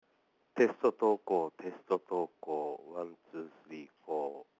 電話でブログ投稿〜BLOGROWN: